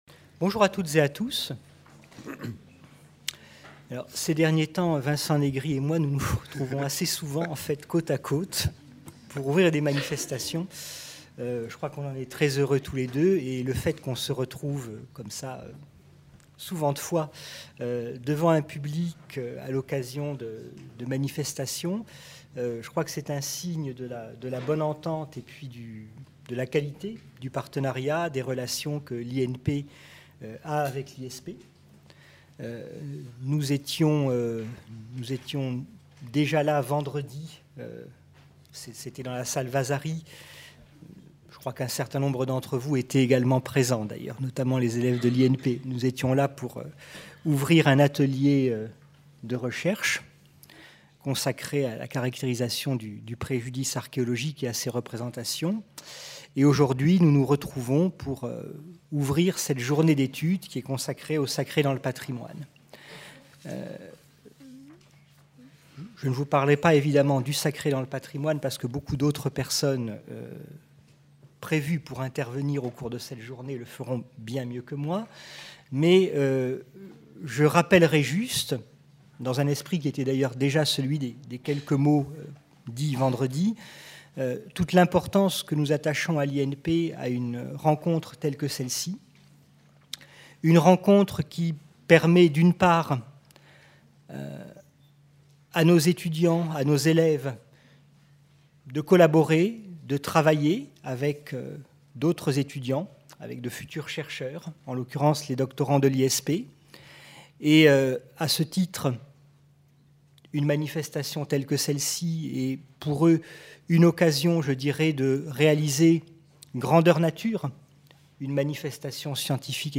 Le sacré dans le patrimoine : Mots d'accueil & présentation de la journée d'étude